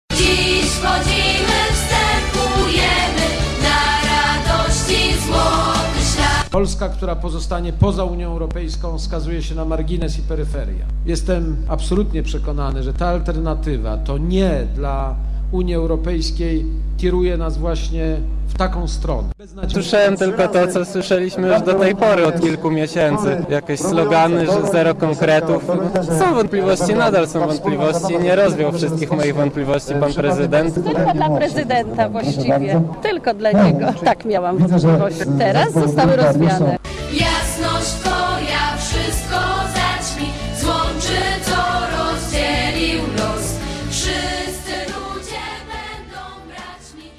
Posłuchaj, co powiedział prezydent i co mówili Torunianie (368 KB)
Na toruńskim Rynku Staromiejskim prezydent spotkał się z mieszczanami.